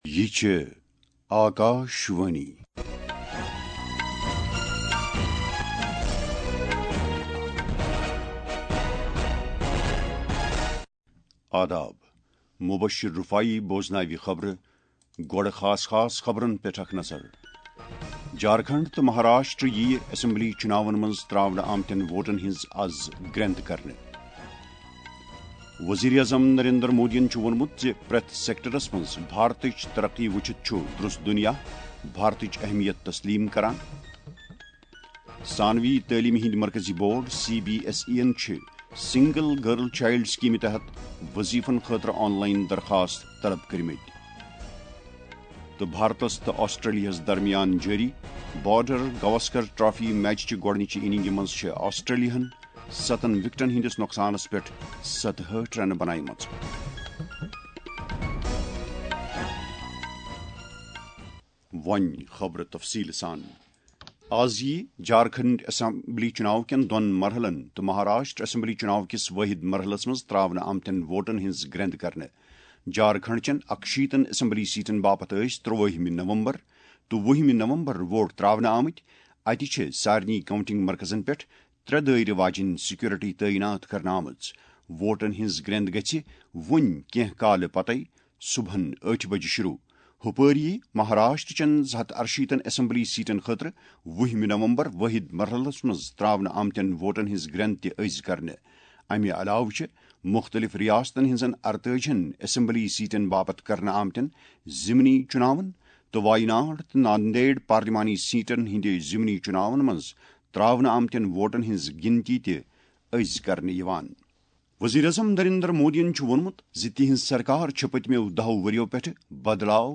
Transcript summary Play Audio Morning News
Audio-of-Morning-National-Kashmiri-N-1.mp3